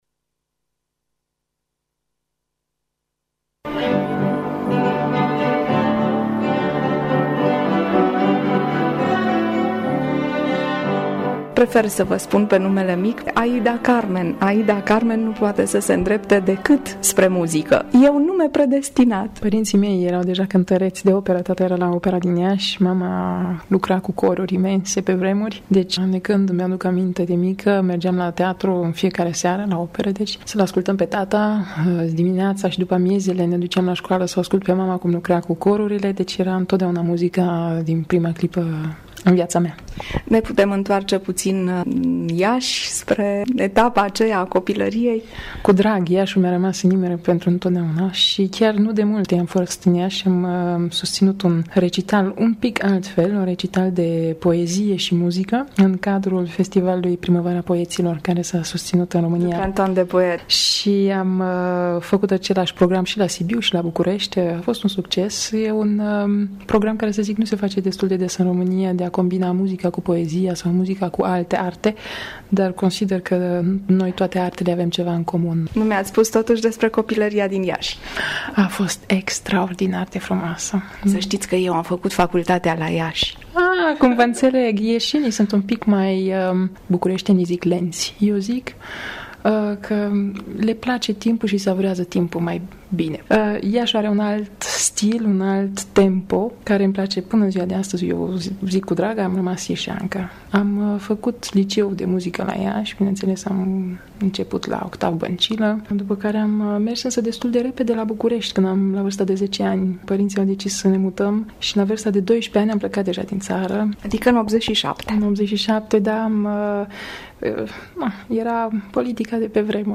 Spiritul ei viu mi s-a relevat la Bruxelles, după un recital, când m-am bucurat ,alături de ea, de Muzică, de Viaţă, de Întâlnire.